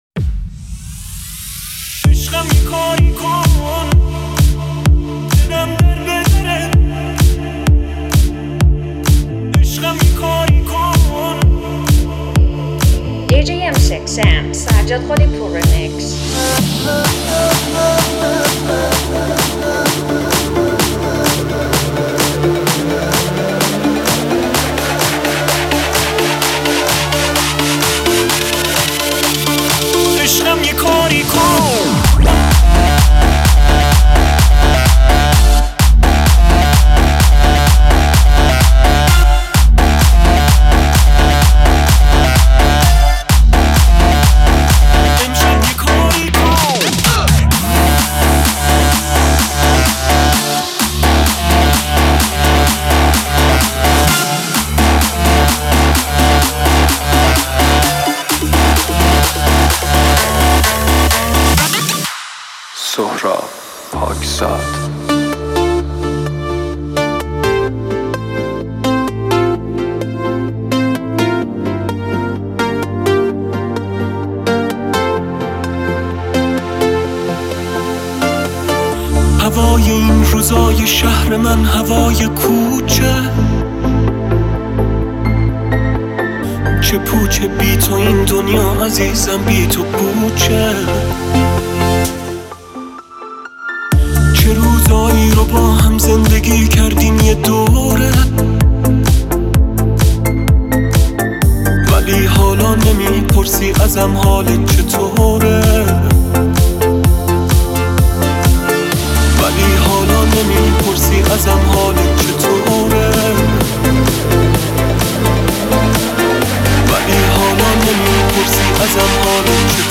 Electro House Mix